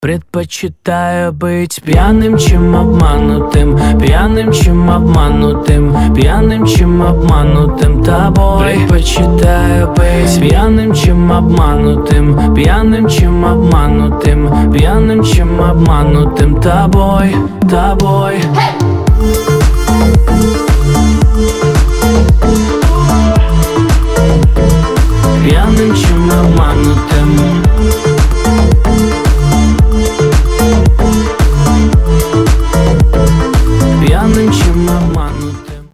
поп
dance
club
house